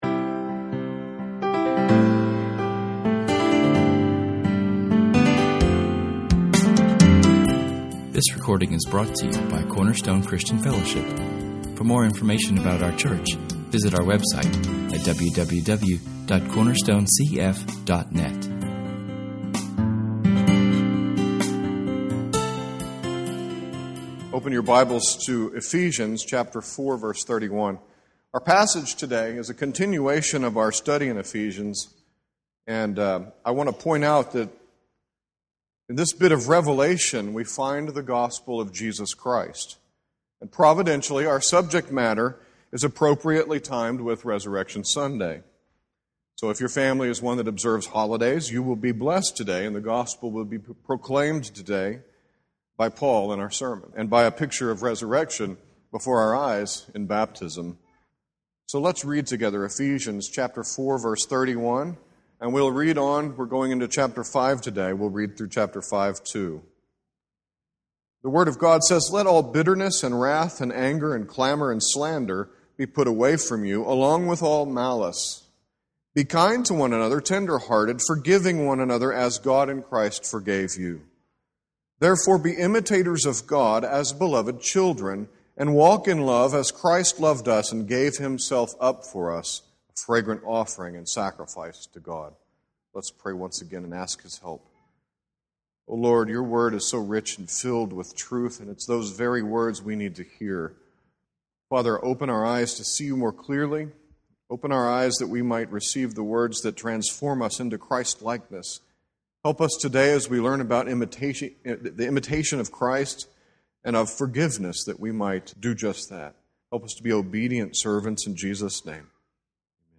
Our sermon features several passages of scripture, and a long reading of the crucifixion and resurrection story from [esvignore]Luke 22:47-24:12[/esvignore].